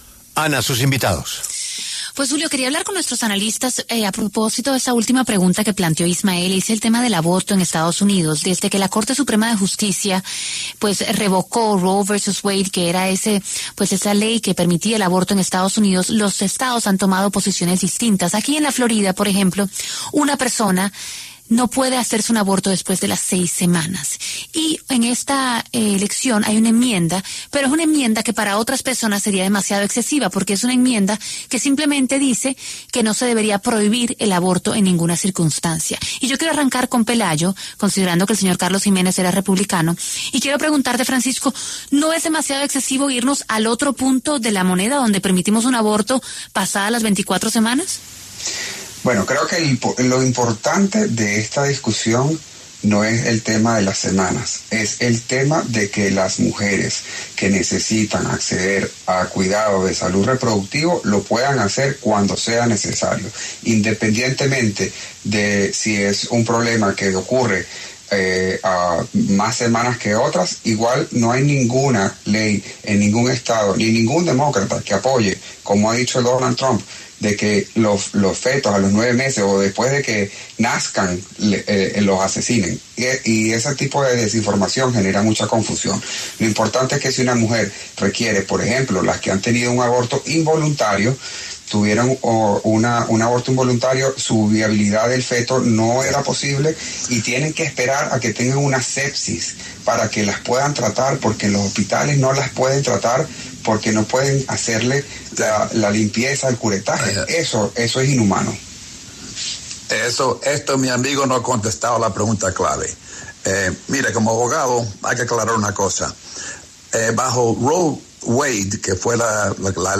Debate: ¿Cuál es la posición de Donald Trump y de Kamala Harris frente al aborto en EEUU?
En La W, dos analistas, uno del lado republicano y otro del demócrata, hablaron sobre diferentes propuestas de Kamala Harris y Donald Trump.